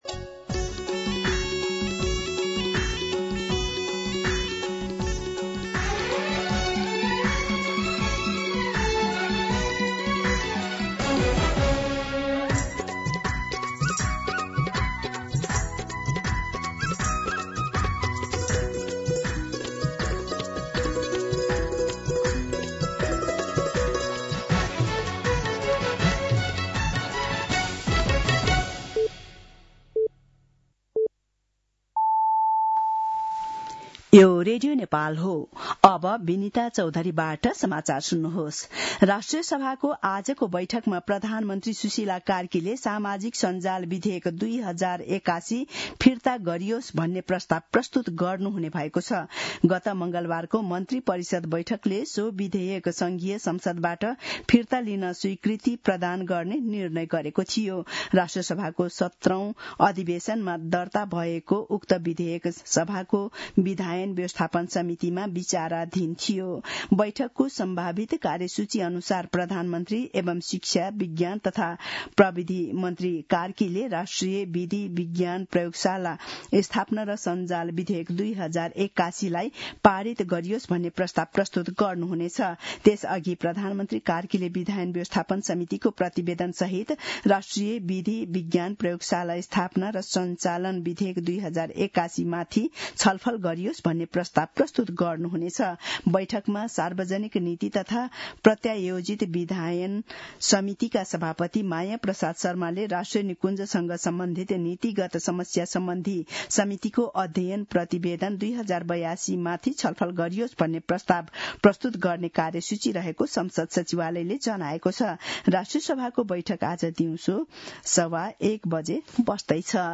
दिउँसो १ बजेको नेपाली समाचार : २३ माघ , २०८२